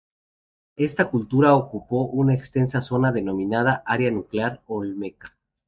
Pronounced as (IPA) /eɡsˈtensa/